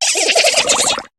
Cri de Cradopaud dans Pokémon HOME.